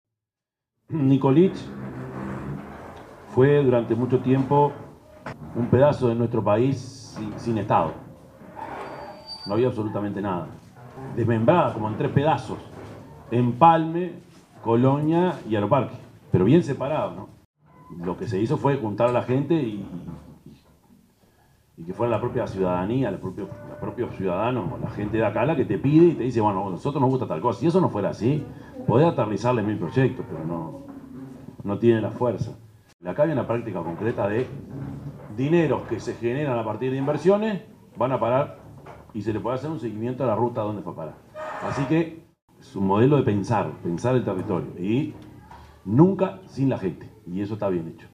intendente_orsi_0.mp3